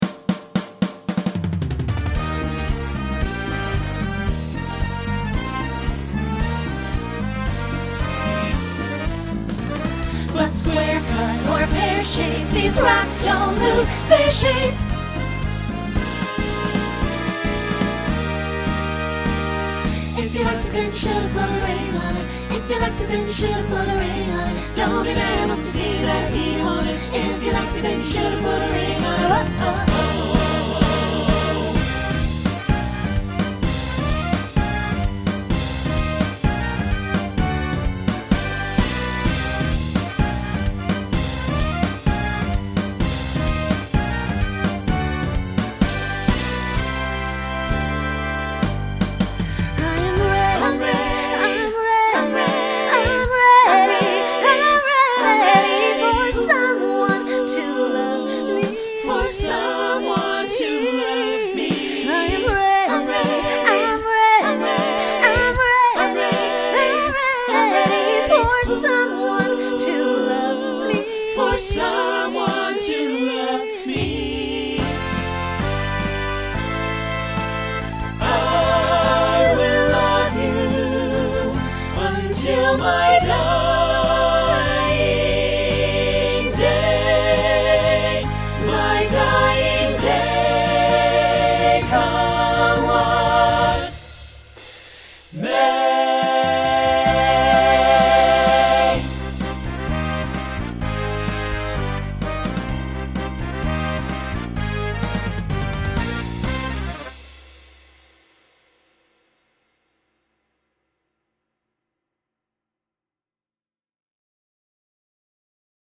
Broadway/Film , Pop/Dance , Rock
Voicing SATB